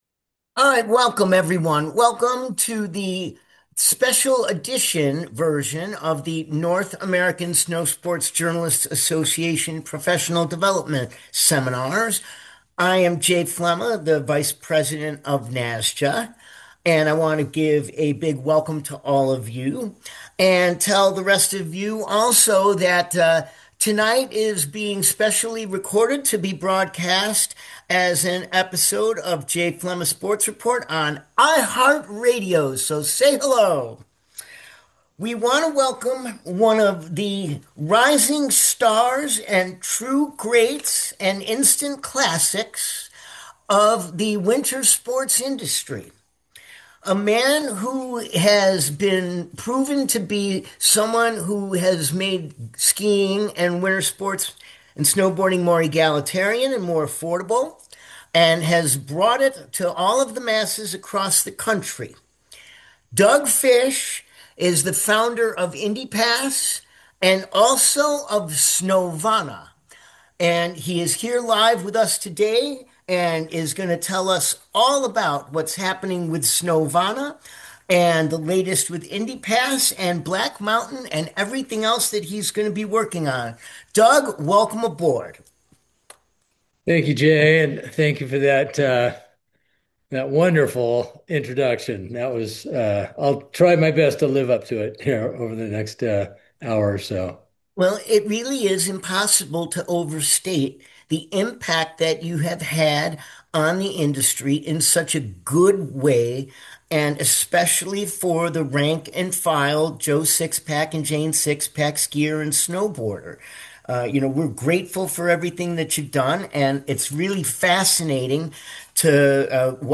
A town hall
a live Zoom town hall